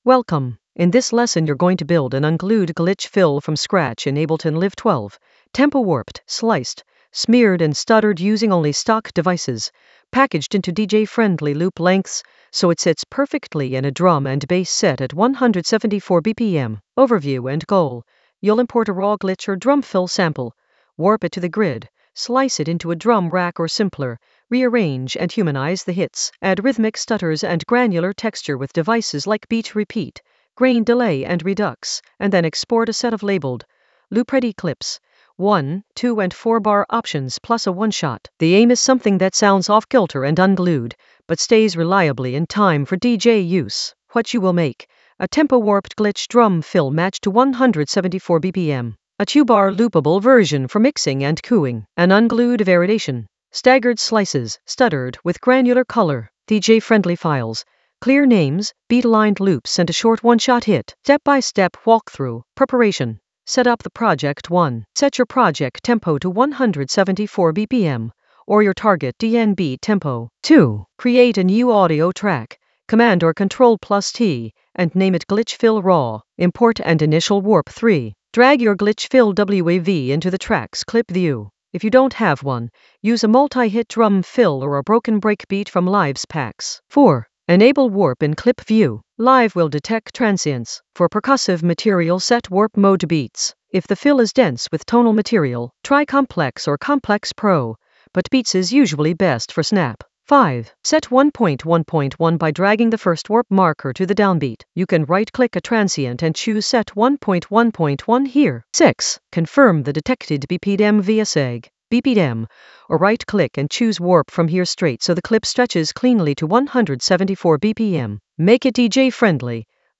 An AI-generated beginner Ableton lesson focused on Unglued edit: warp a glitch fill from scratch in Ableton Live 12 with DJ-friendly structure in the Sampling area of drum and bass production.
Narrated lesson audio
The voice track includes the tutorial plus extra teacher commentary.